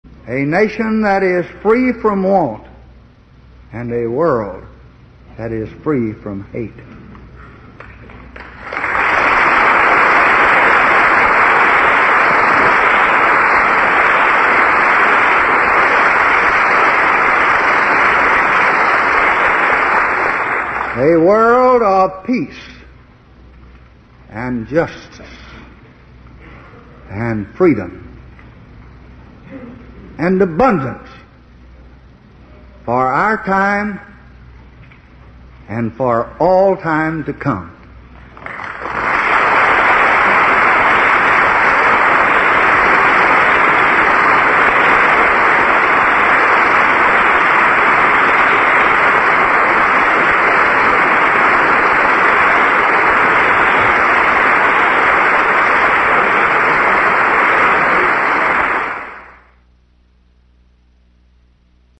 Tags: Lyndon Baines Johnson Lyndon Baines Johnson speech State of the Union State of the Union address President